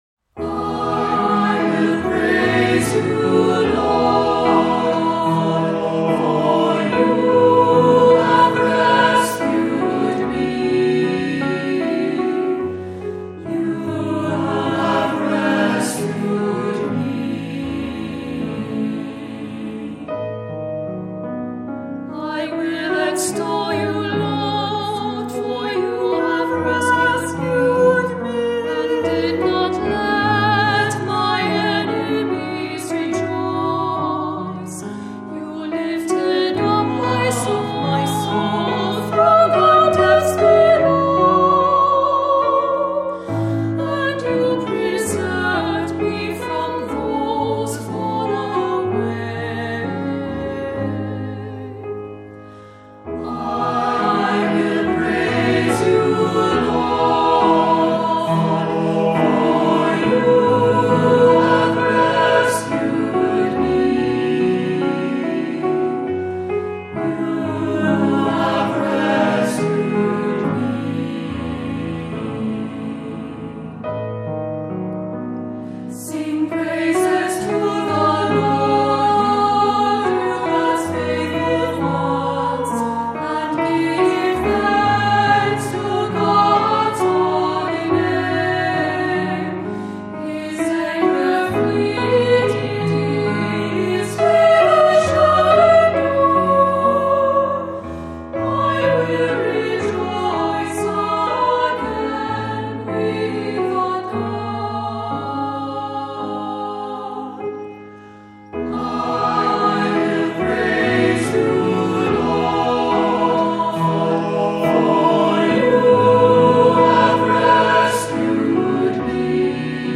Voicing: Cantor, assembly,SATB